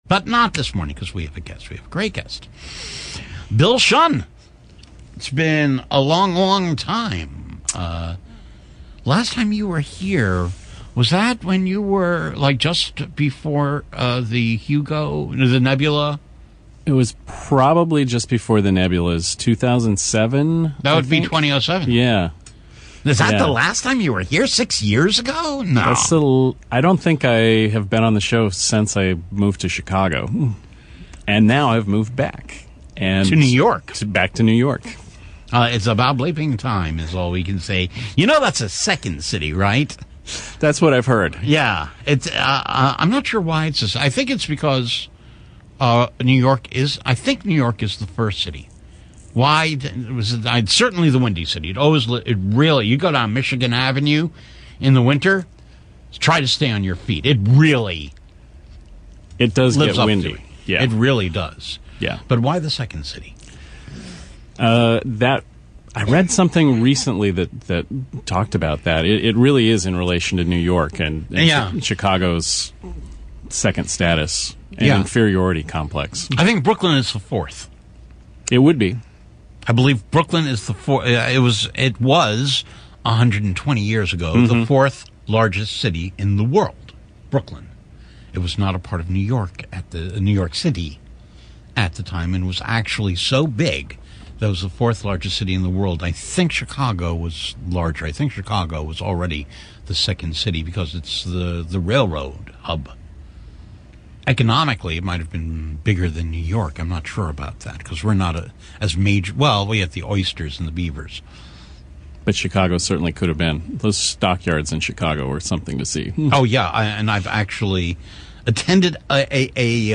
That's how I found myself in Harlem in the wee hours of Thursday morning, smack-dab in the middle of the beautiful City College of New York campus. Thanks to its highly publicized financial problems , WBAI is sharing studio space with WHCR until it either moves into a new space in Brooklyn or collapses altogether.